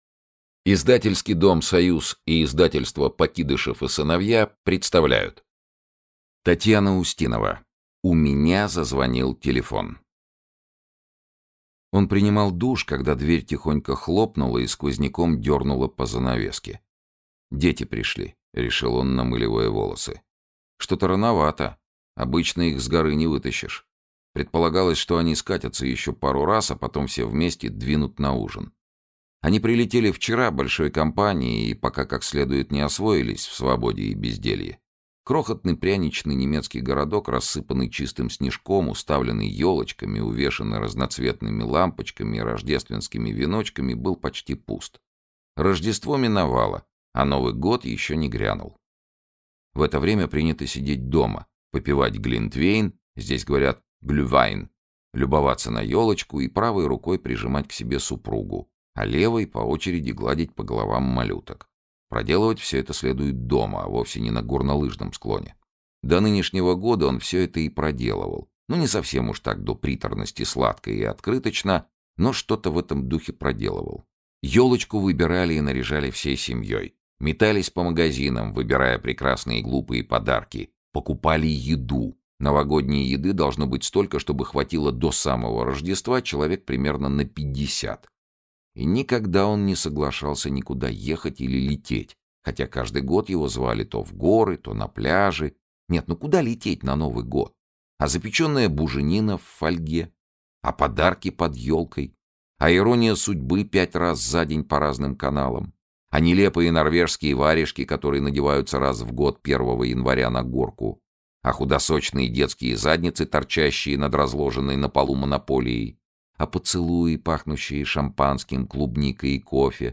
Аудиокнига У меня зазвонил телефон | Библиотека аудиокниг
Aудиокнига У меня зазвонил телефон Автор Татьяна Устинова Читает аудиокнигу Сергей Чонишвили.